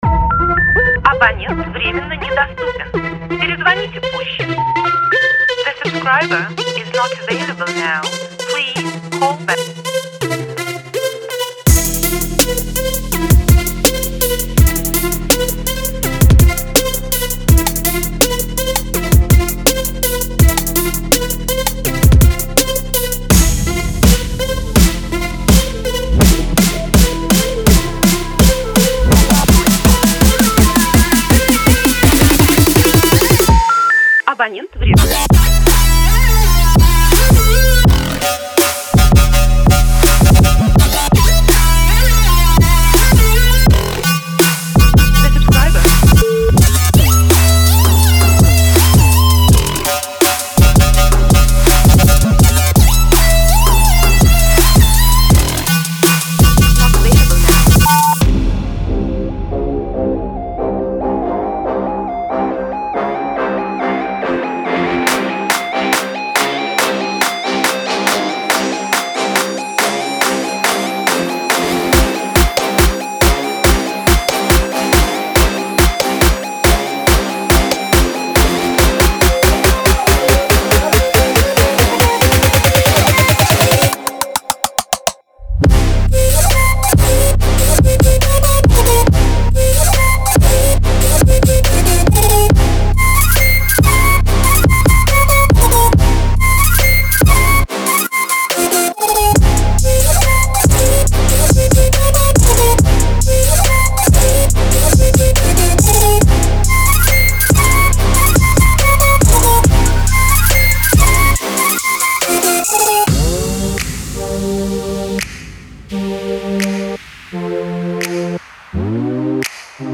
溢价专业陷阱制作的声音。
Hybrid Trap仍然是大型EDM音乐节上的领先声音之一，有了此包，就可以轻松制作出这种风格的出色作品。
在不同的键中击中808个多样本。
您可以在演示轨道中听到所有这些声音。